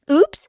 На этой странице собраны звуки «упс» — весёлые и искренние реакции на мелкие промахи.
Женский звук упс